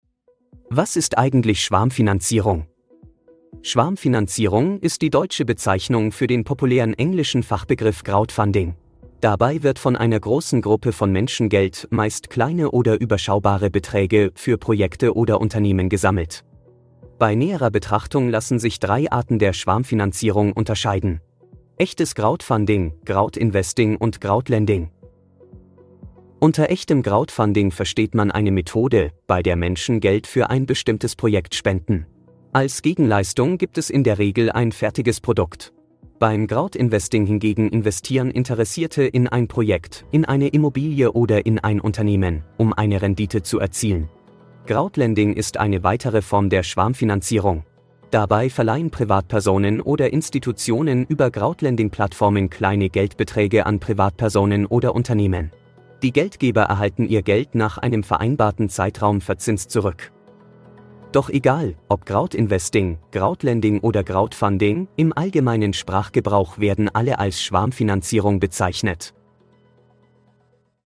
Episode wurde mit KI erstellt